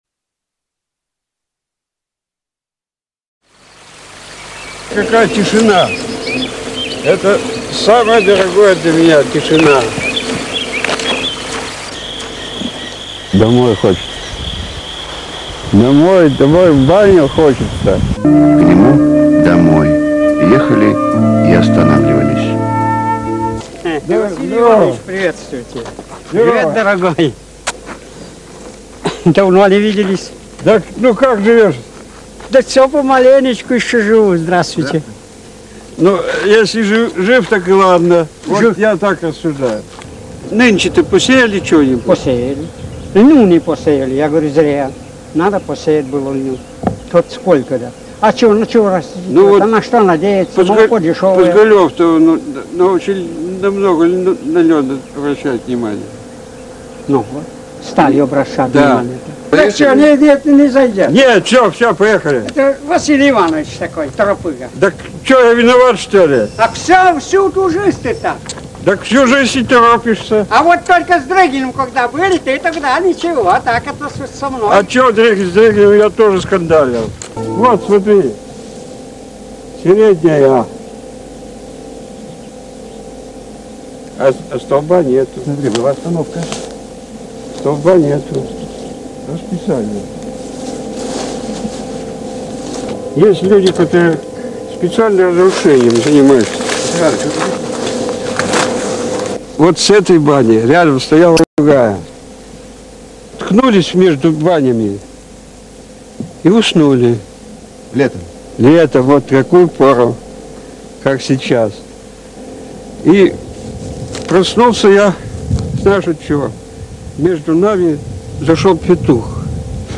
Ведущие: Василий Белов